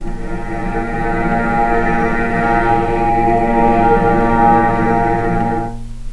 healing-soundscapes/Sound Banks/HSS_OP_Pack/Strings/cello/sul-ponticello/vc_sp-A#2-pp.AIF at 48f255e0b41e8171d9280be2389d1ef0a439d660
vc_sp-A#2-pp.AIF